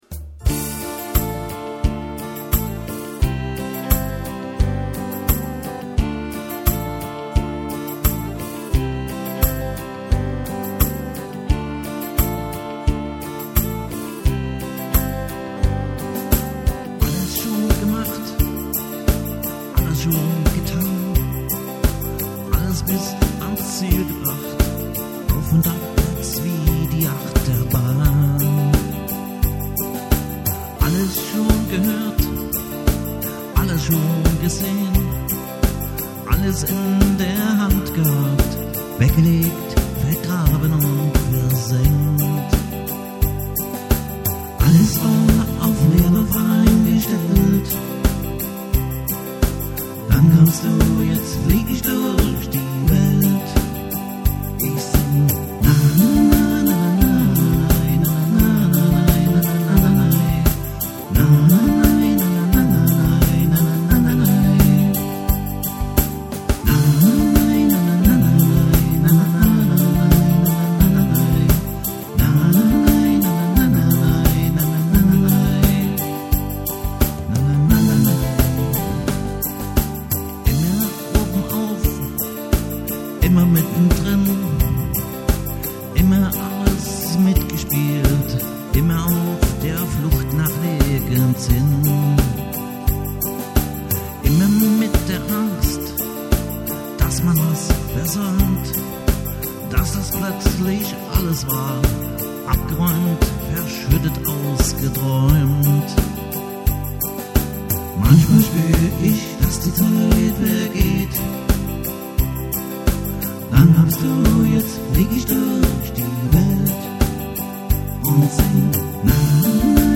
- Livemusik mit Gesang
• Alleinunterhalter